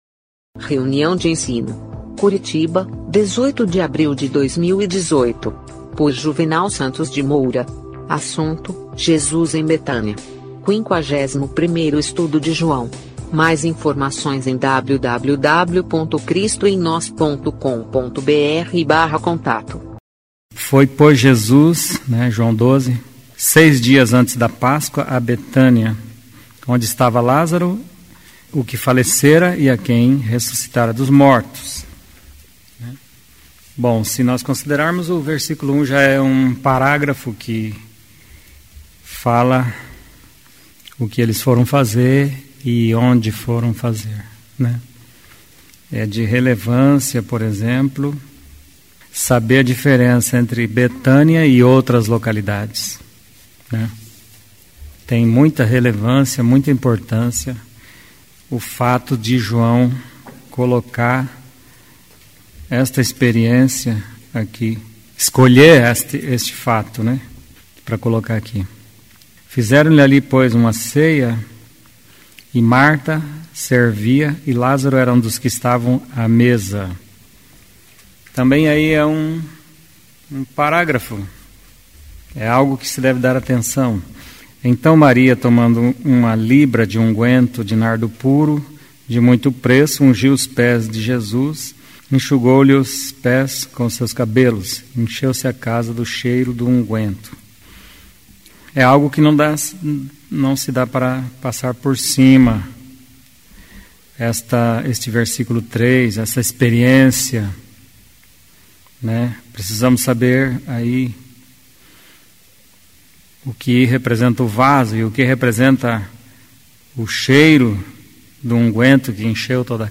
Jesus em Betânia (51º estudo de João) | Cristo em Nós